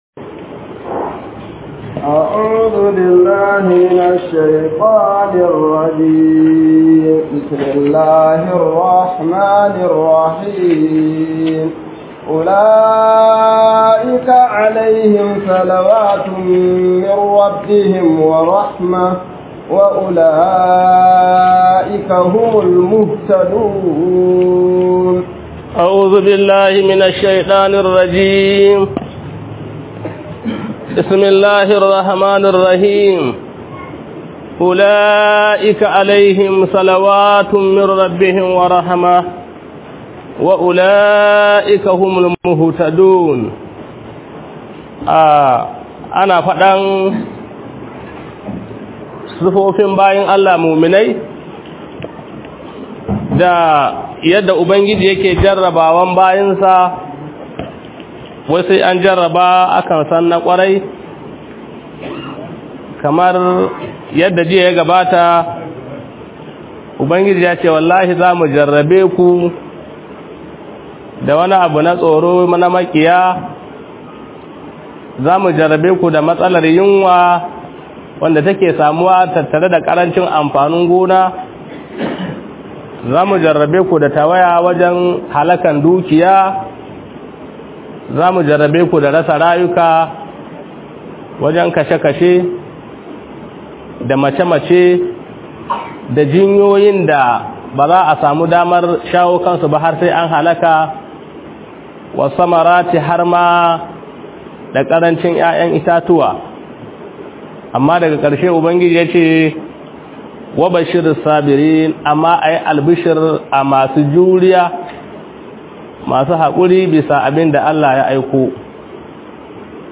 011_Tafsir_Ramadan.mp3